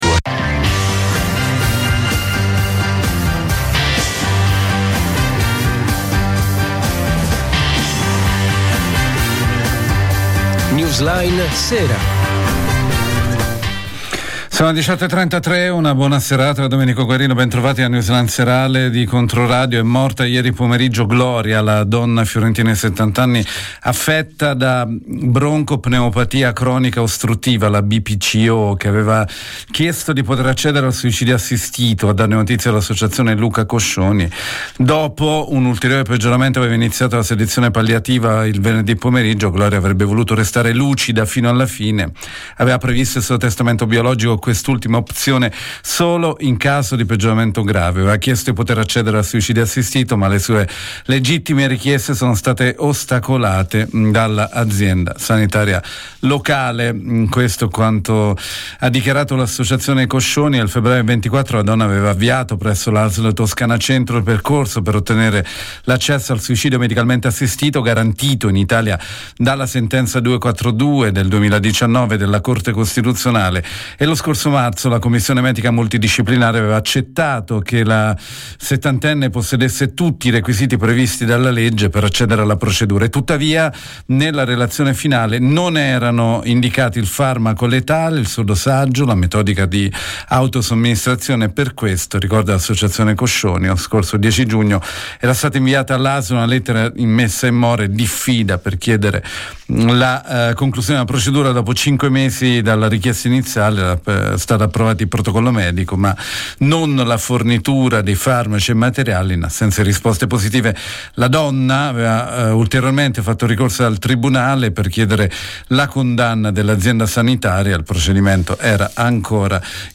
Conferenza stampa di presentazione del Rapporto annuale - LA CONGIUNTURA E LA LEGGE DI BILANCIO: I RIFLESSI SULLA TOSCANA - IrpetIrpet
Regione Toscana | Sala Pegaso di Palazzo Strozzi Sacrati in Piazza Duomo 10 a Firenze.